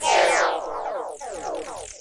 GIBBERISH 2
描述：卡通的声音让我说出乱码，然后复制和分层，并加速每一层。使用CA桌面麦克风录制。在Audacity中处理。
Tag: TECHNOLOGIC 卡通 科技 胡言乱语 高科技 什么最赫克 电脑 声乐 外星人 高科技